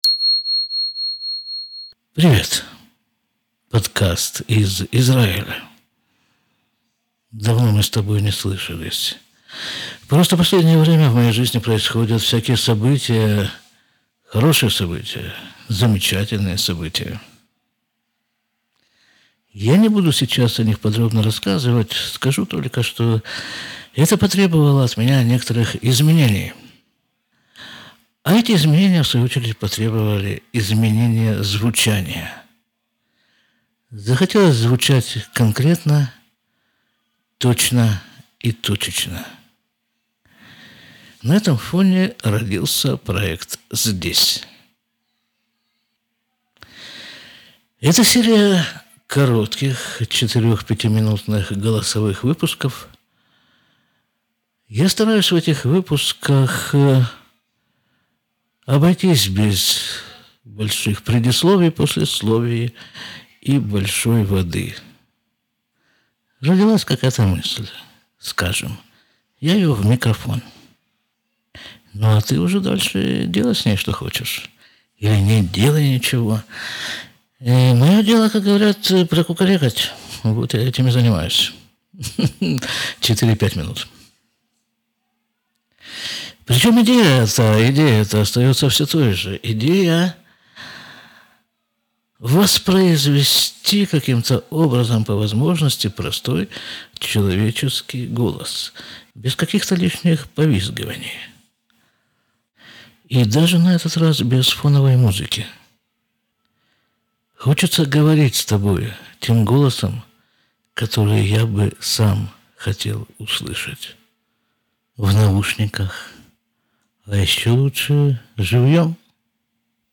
серия коротких, точечных голосовых выпусков без воды, музыки и объяснений.
Простой человеческий голос.
Мысль — в микрофон.